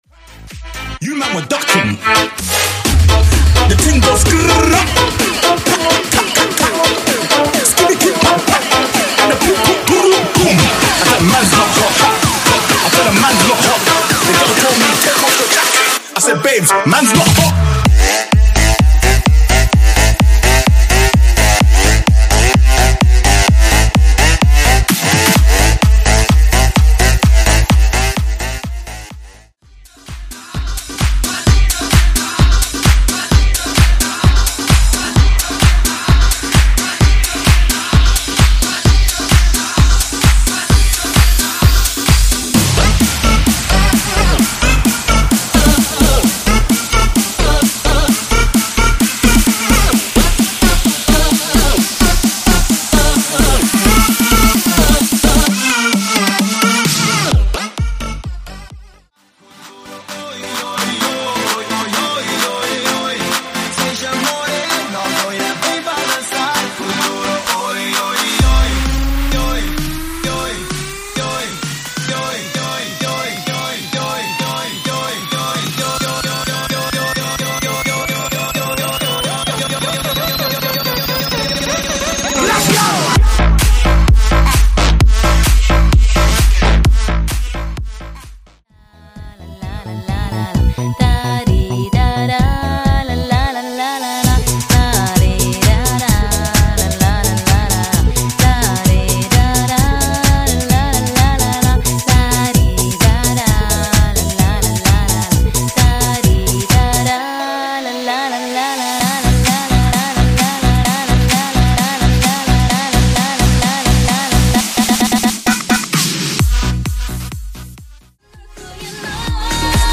Genre: BASS HOUSE
Clean BPM: 125 Time